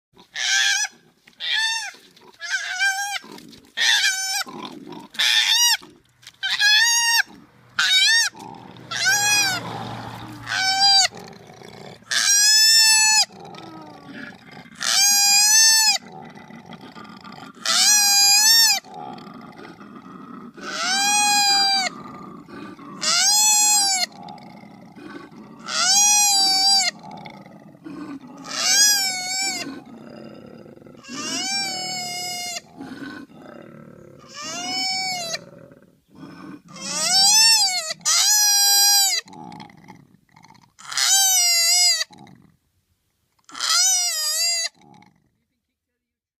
Звуки коалы
Драка коал одна кричит другая рычит